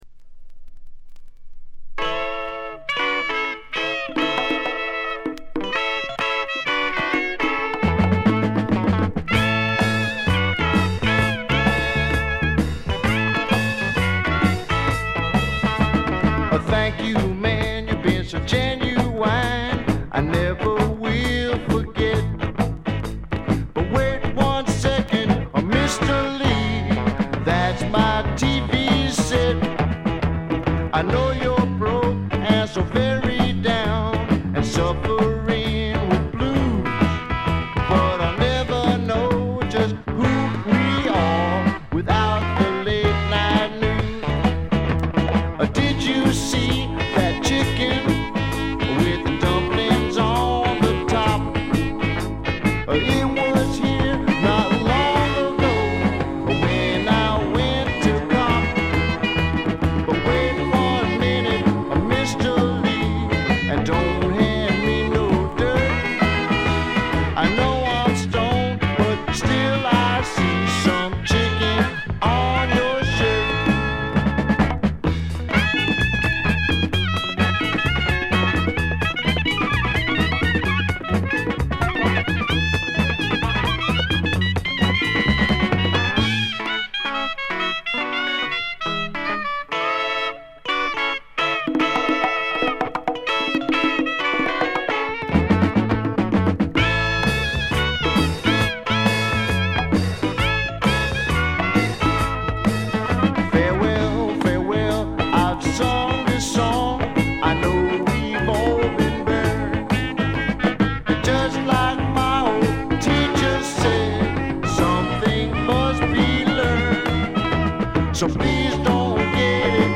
軽微なチリプチ程度。
試聴曲は現品からの取り込み音源です。
Recorded At - Sound Exchange Studios